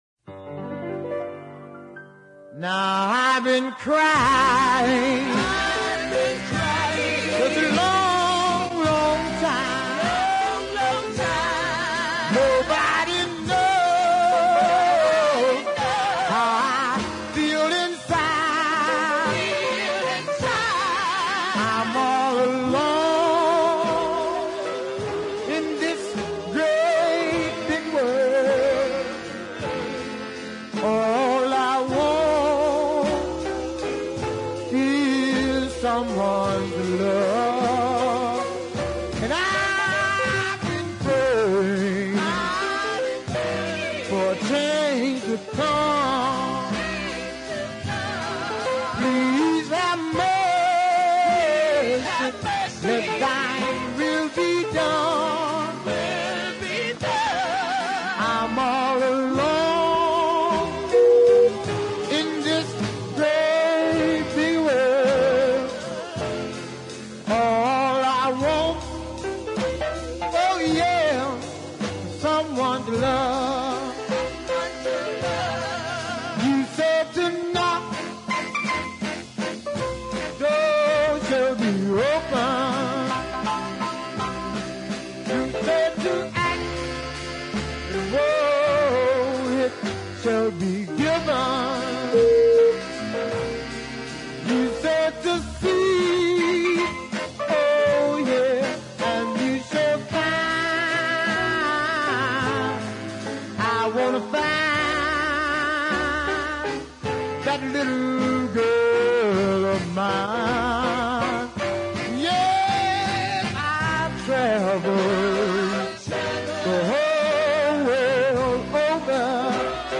Georgia singer
hard hitting ballad
fine hard hitting tones on this one